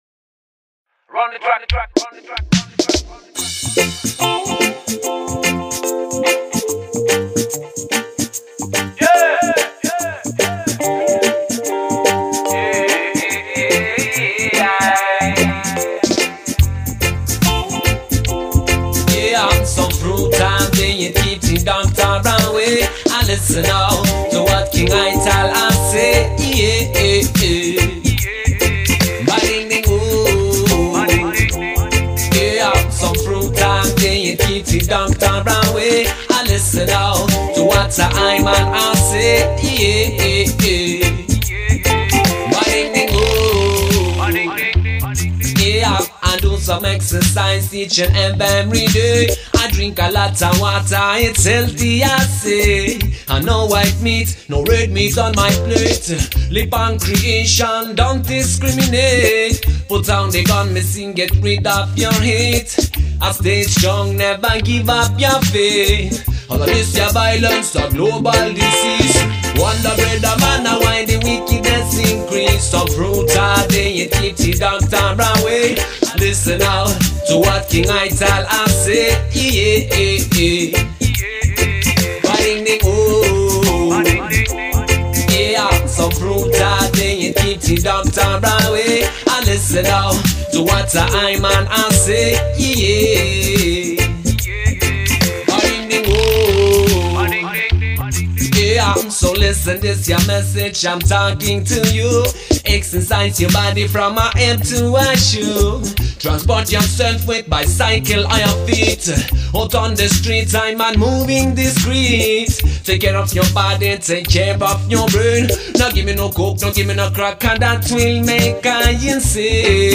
vocal. different style!